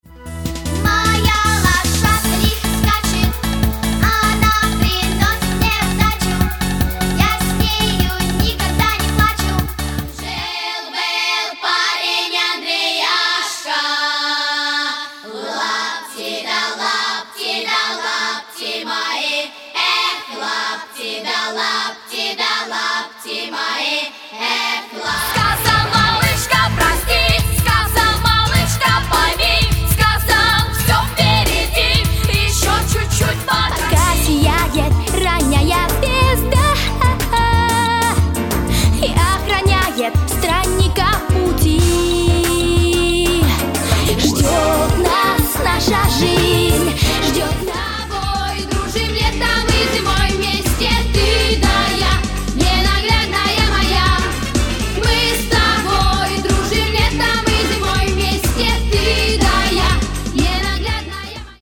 Пока что я подготовил всего несколько коротеньких семплов - Dean Caddy> SVS Skeleton > 5150 poweramp > 4x12 > sm57 > *.mp3 А в ближайшем будущем готовится, пожалуй, самый масштабный мой тест В архиве записаны для сравнения два коротеньких...
Чистый канал модификация фендера.